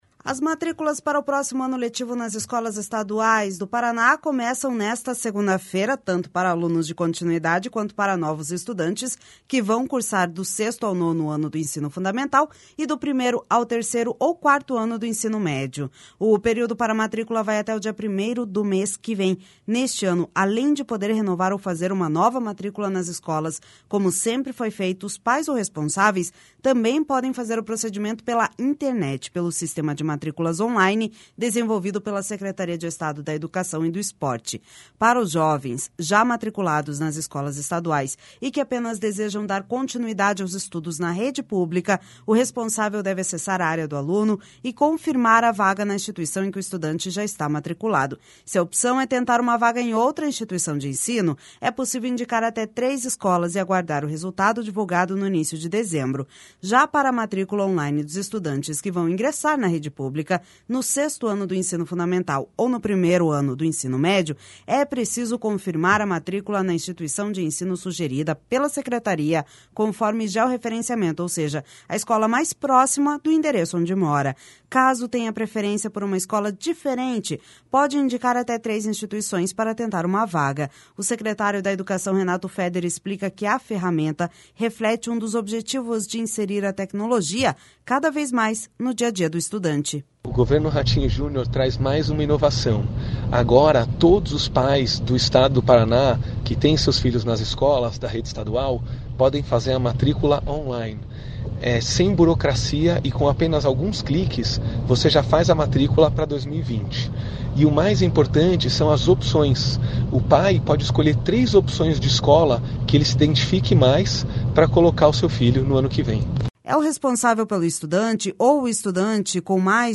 O secretário da Educação, Renato Feder, explica que a ferramenta reflete um dos objetivos de inserir a tecnologia cada vez mais no dia a dia do estudante.// SONORA RENATO FEDER//É o responsável pelo estudante, ou o estudante que tiver mais de 18 anos, que deve acessar a plataforma, após fornecer CPF e número de celular, para onde vai ser enviado um código de validação via SMS.